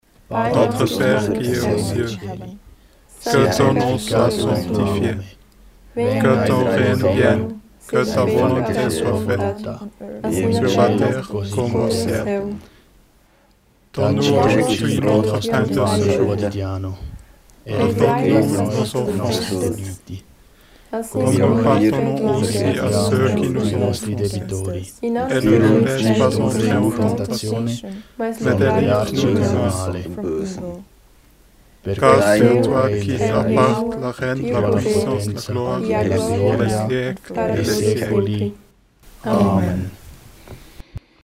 Vater unser in verschiedenen Sprachen
Vater unser als Kanon
Aufbauend bei einer Stimme können immer mehr Stimmen in anderen Sprachen hinzugefügt werden und so ein Gemurmel erzeugt werden.
Dabei sind der Inhalt und die einzelnen Wörter nicht mehr verständlich aber die Grundkonstruktion des Rhythmus erkennt jeder sofort.
Vater_unser_Mix_V2.mp3